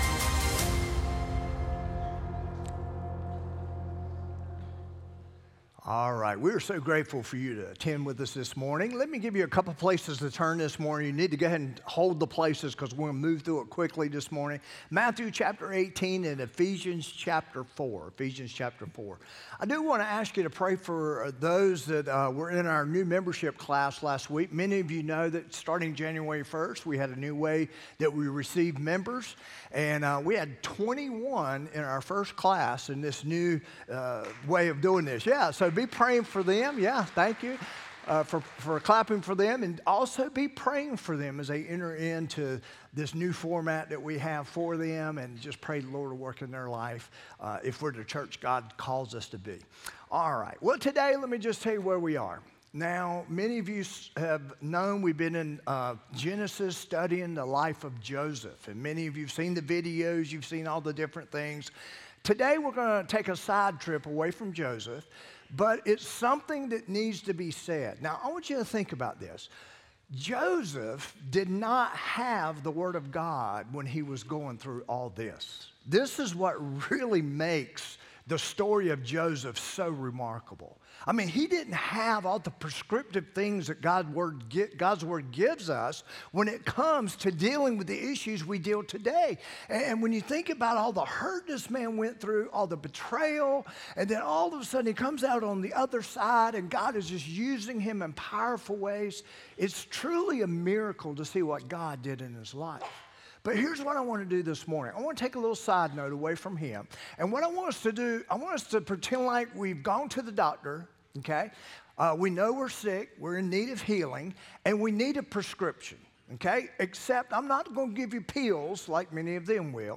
2-9-25-sermon-audio.m4a